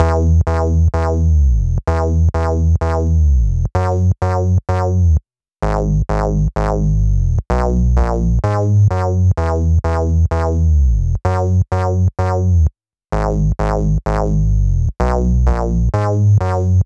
VTDS2 Song Kit 08 Pitched Sneaking On The DF Bass.wav